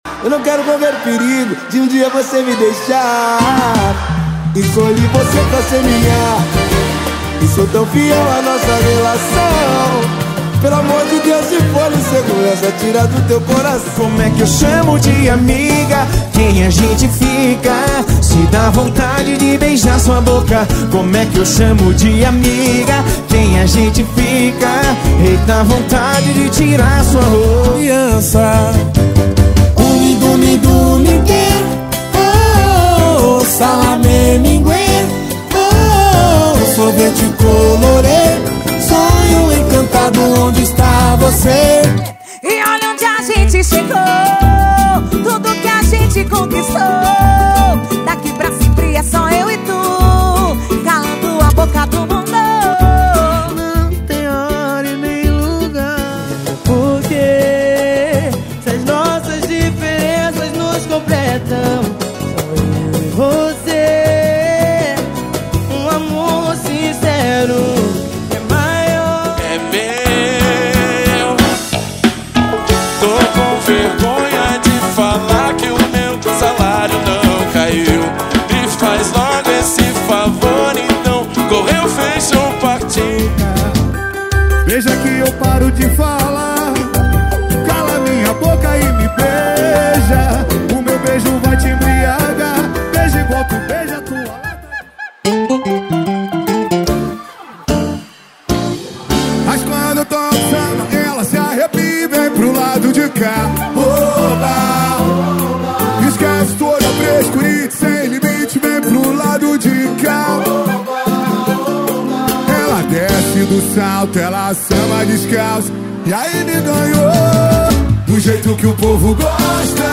Os Melhores Pagode + Samba do momento estão aqui!!!
• Pagode e Samba = 50 Músicas